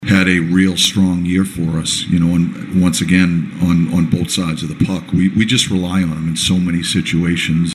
Coach Mike Sullivan said Sidney Crosby is a true team leader.